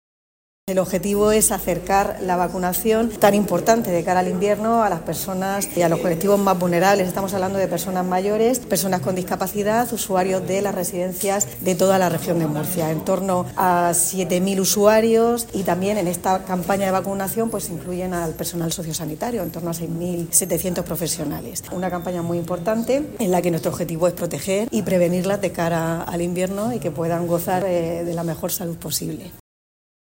Sonido/ Declaraciones de la consejera de Política Social, Conchita Ruiz [mp3], sobre la importancia de la vacunación en las residencias.